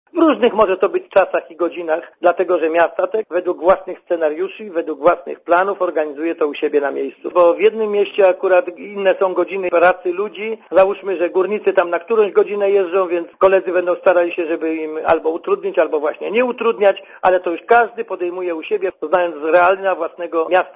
Dla Radia Zet mówi